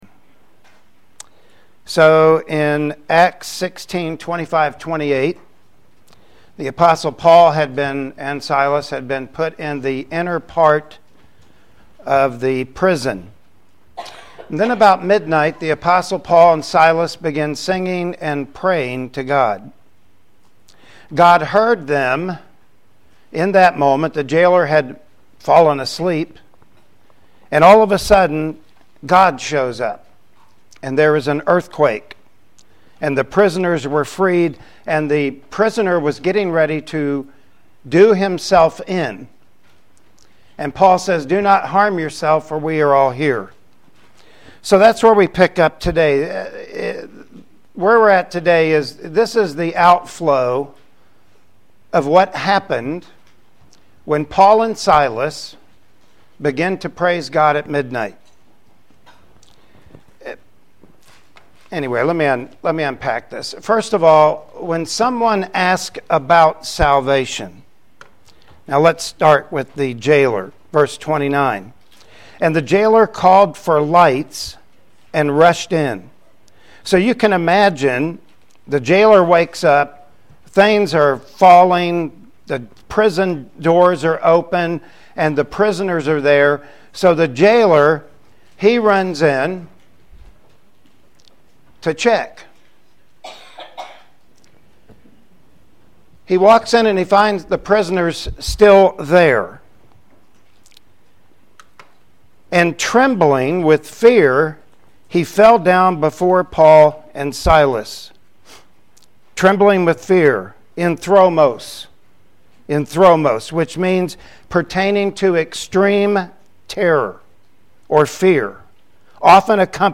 Sunday Morning Worship Service